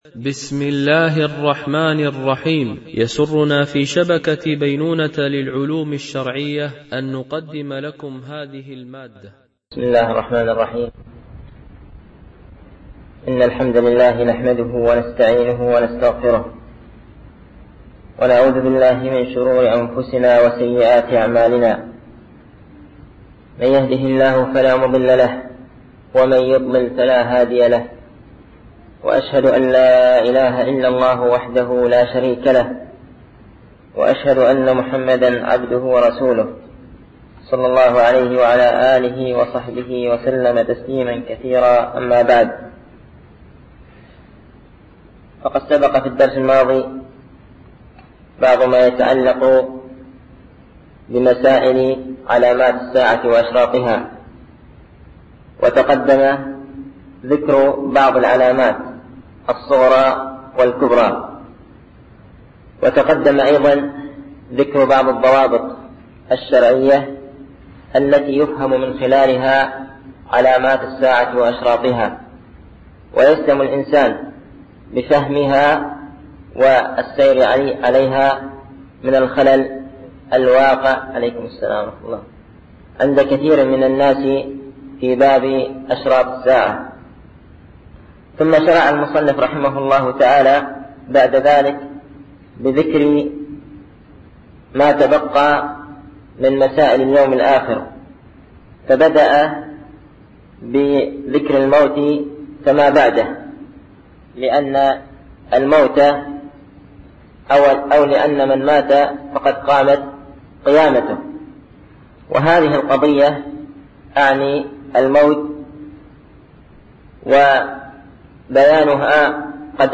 الألبوم: شبكة بينونة للعلوم الشرعية التتبع: 39 المدة: 45:47 دقائق (10.52 م.بايت) التنسيق: MP3 Mono 22kHz 32Kbps (CBR)